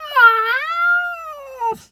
cat_2_meow_emote_01.wav